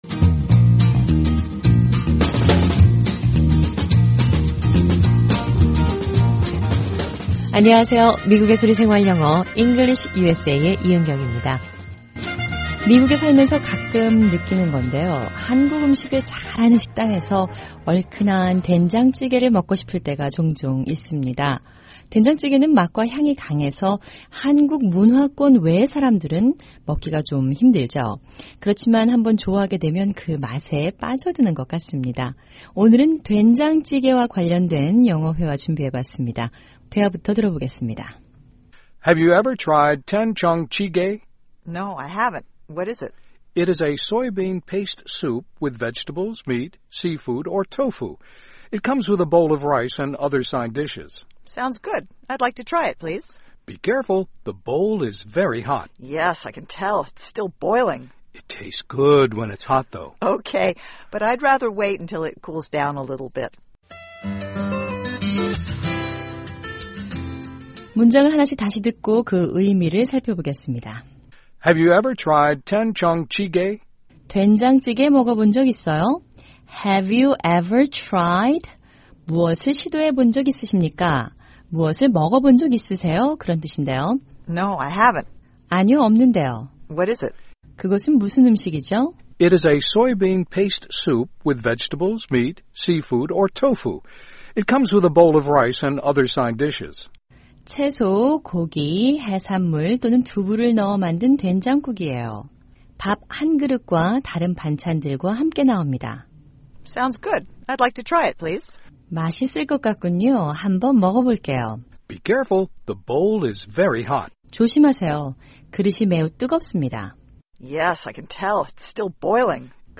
일상생활에서 사용하는 영어를 배우는 'VOA 현장영어' 오늘은 전통적 한국 음식인 된장찌개를 소개하는 대화 들어보겠습니다.